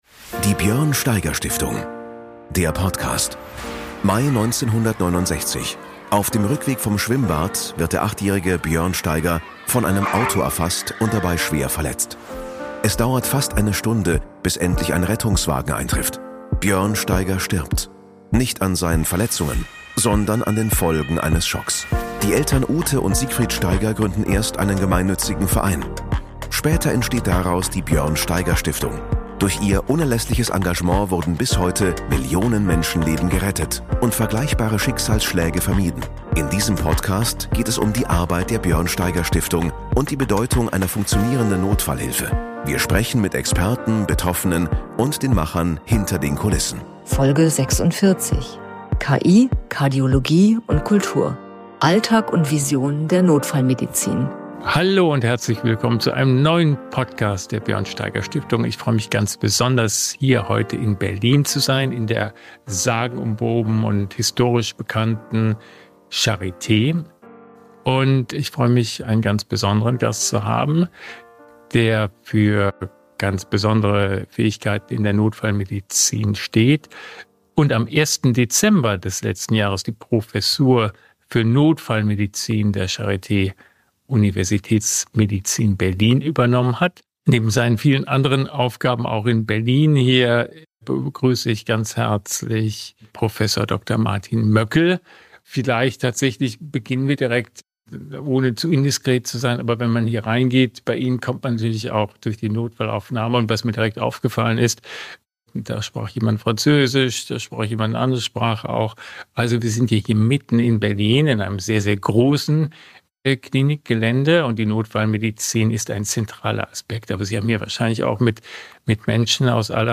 Beschreibung vor 9 Monaten Diese Folge wurde aufgenommen an einem Ort, der wie kaum ein anderer für medizinische Exzellenz und Geschichte steht – der Berliner Charité.